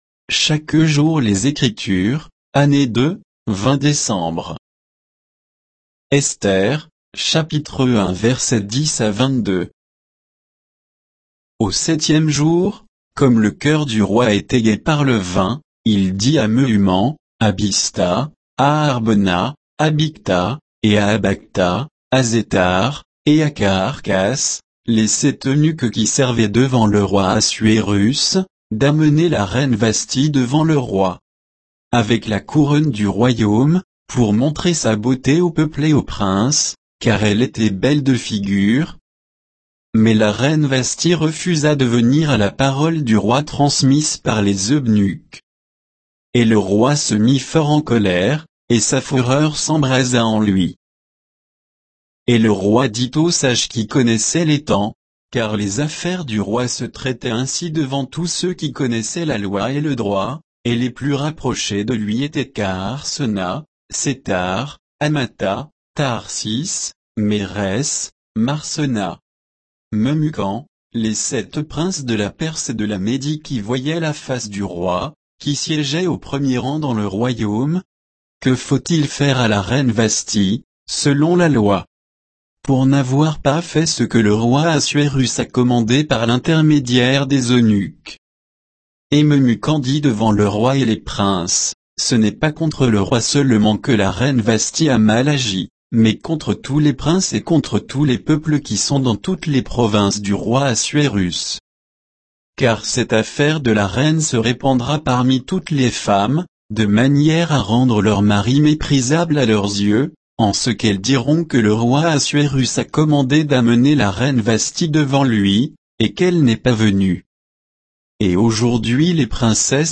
Méditation quoditienne de Chaque jour les Écritures sur Esther 1